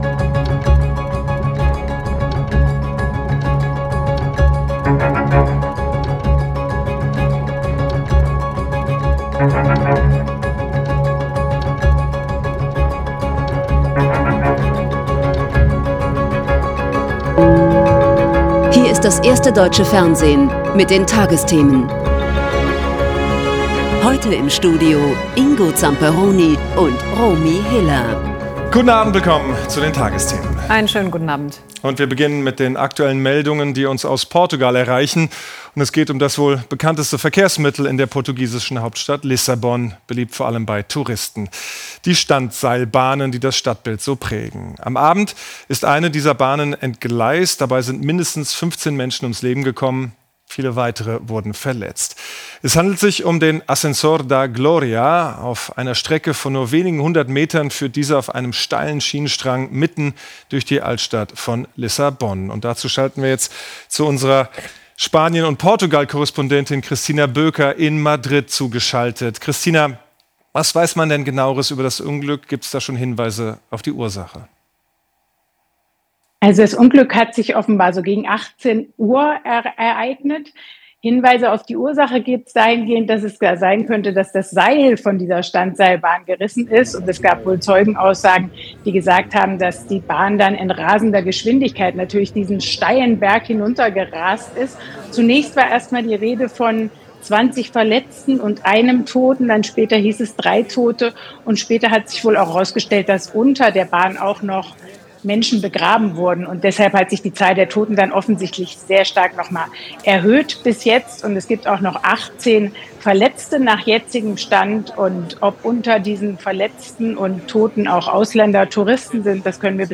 … continue reading 2540 에피소드 # Tägliche Nachrichten # Nachrichten # Tagesschau